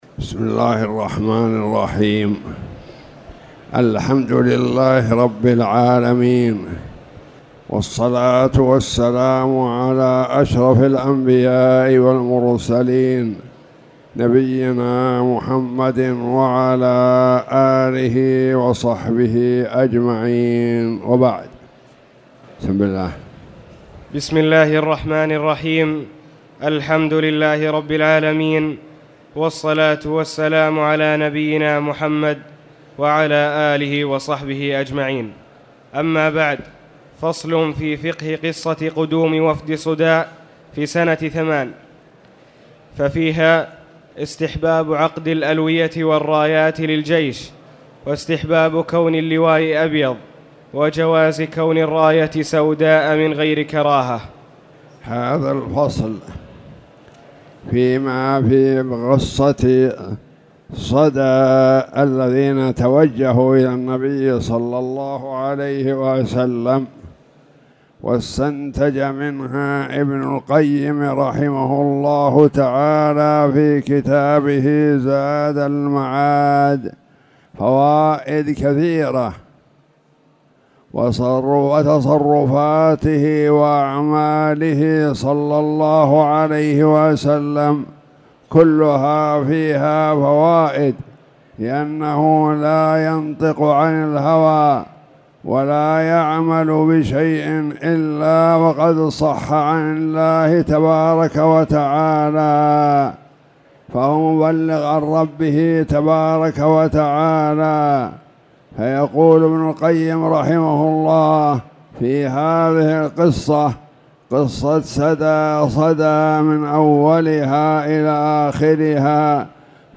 تاريخ النشر ٢٣ شعبان ١٤٣٨ هـ المكان: المسجد الحرام الشيخ